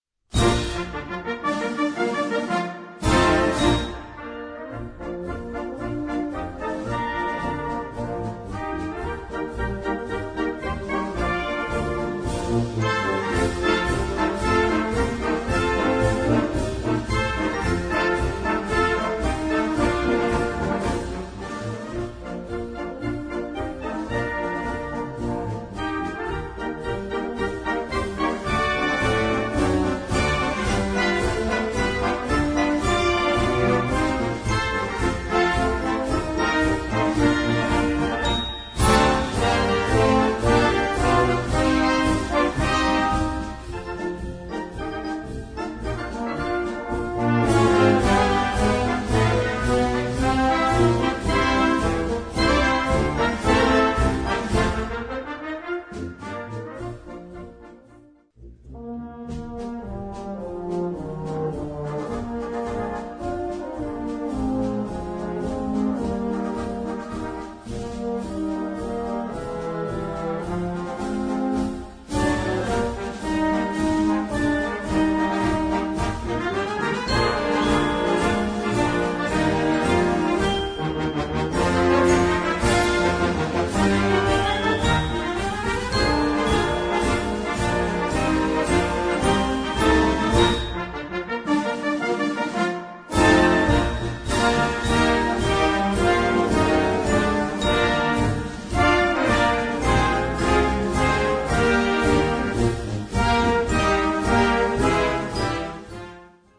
Gattung: Straßenmarsch
Besetzung: Blasorchester